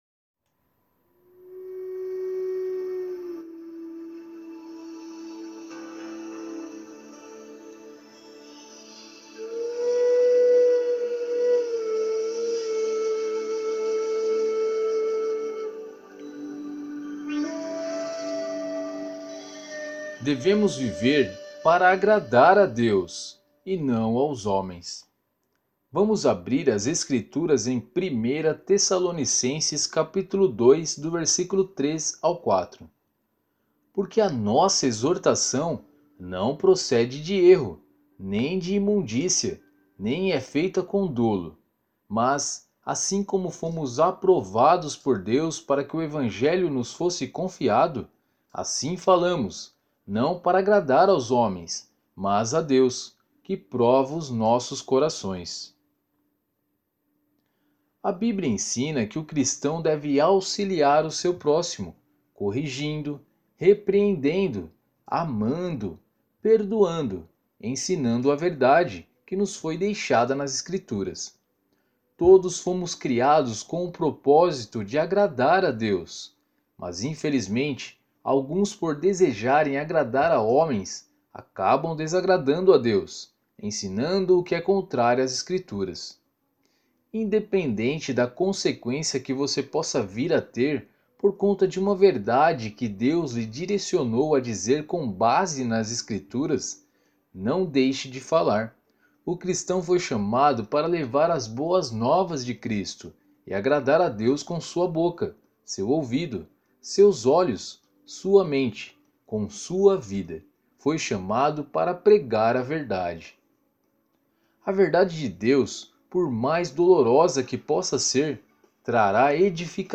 Você também pode ouvir a narração do Alimento Diário!